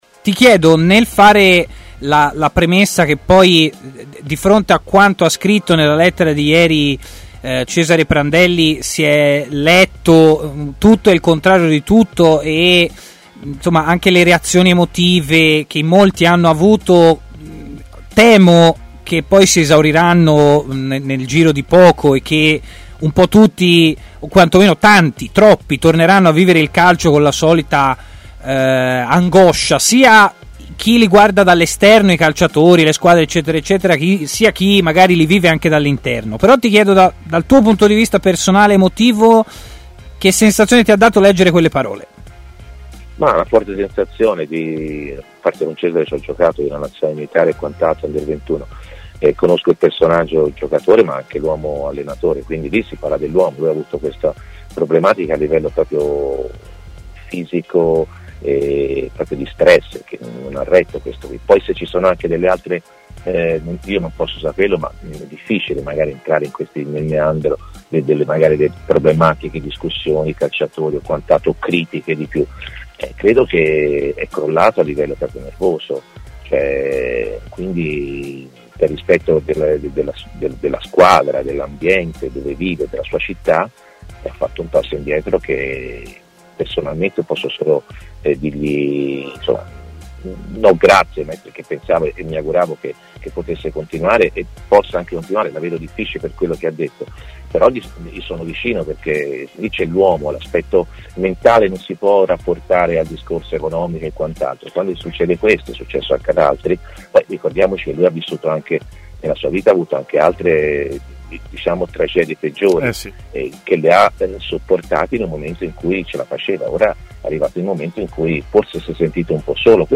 L'opinionista Antonio Di Gennaro, ex centrocampista tra le altre della Fiorentina e oggi commentatore tecnico di Rai Sport, ha parlato in diretta a Stadio Aperto, trasmissione di TMW Radio. Tra i temi trattati anche l'addio improvviso alla panchina viola di Cesare Prandelli.